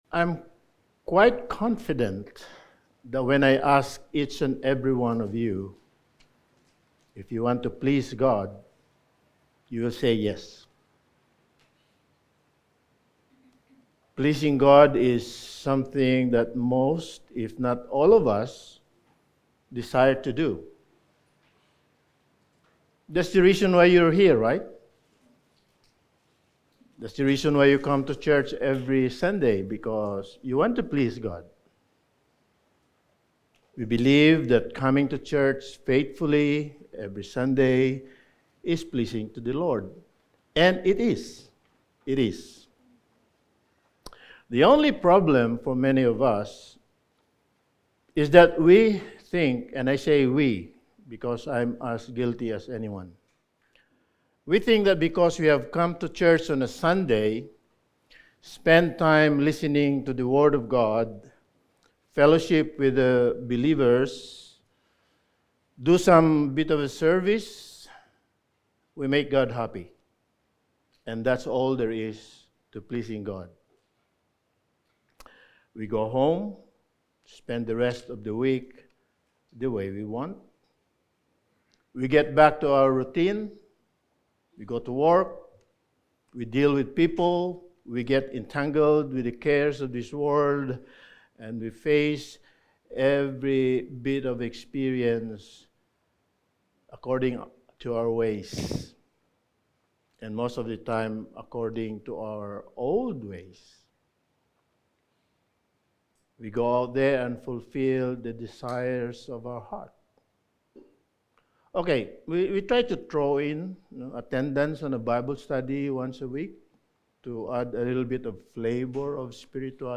Sermon
1 Thessalonians 4:1-12 Service Type: Sunday Morning Sermon 8 « Why Do You Speak to Them in Parables?